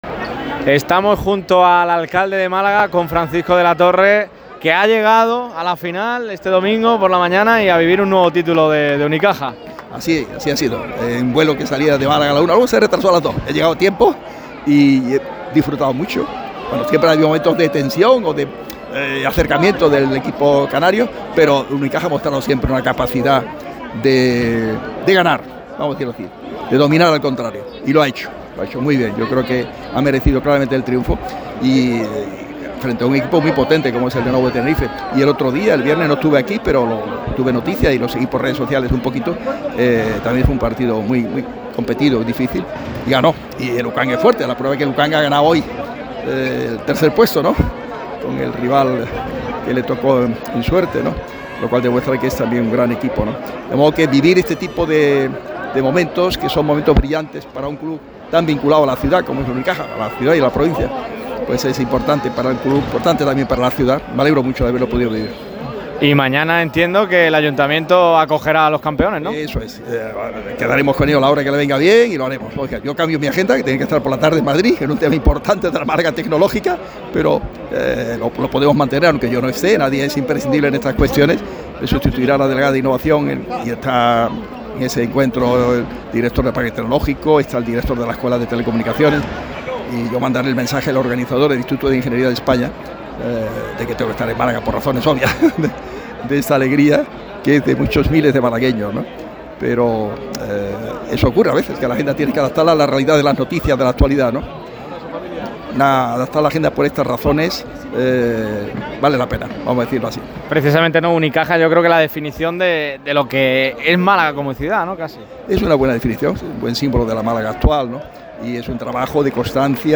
El alcalde de Málaga ha hecho acto de presencia en Belgrado para animar al Unicaja y celebrar la consecución del tercer título europeo de los cajistas. El edil ha dedicado unas emotivas palabras a la entidad en el micrófono rojo de Radio MARCA Málaga.